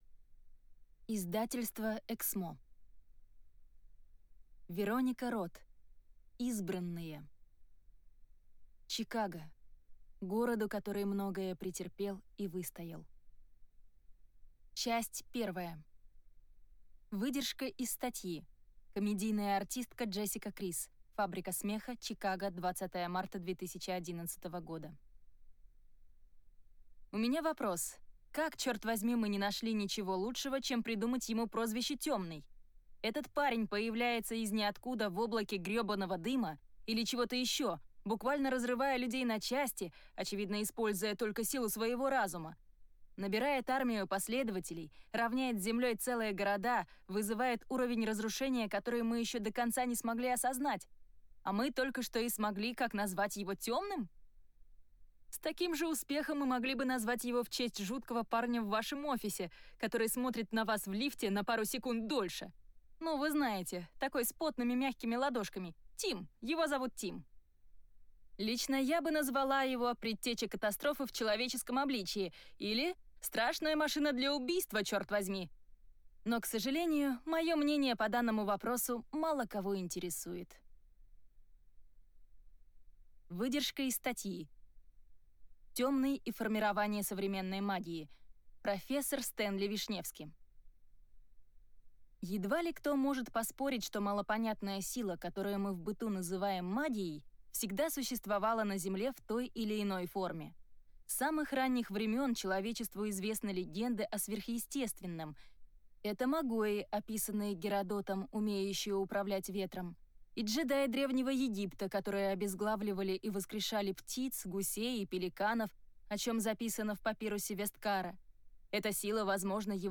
Аудиокнига Избранные - купить, скачать и слушать онлайн | КнигоПоиск